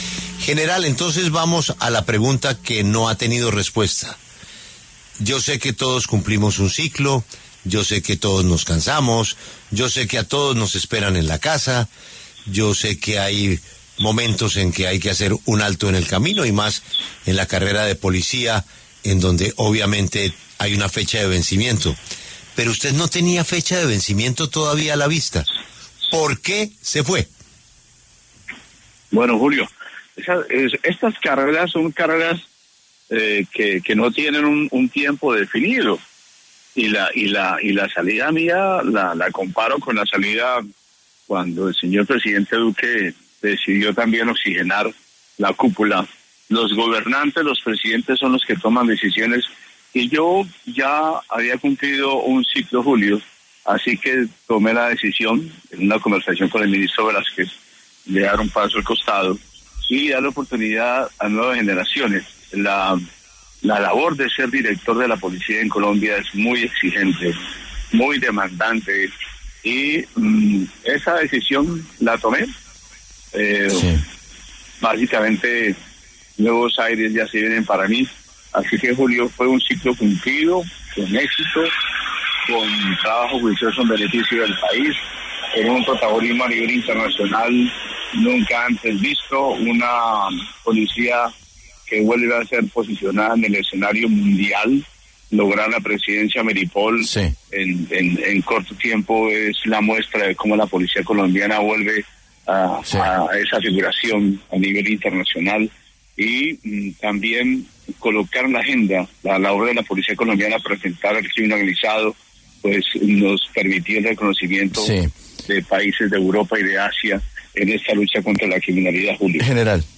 El general (r) Salamanca se retiró sin dar mayores detalles sobre lo que llevó a su salida, sin embargo, ahora pasó por los micrófonos de La W donde fue consultado sobre el tema.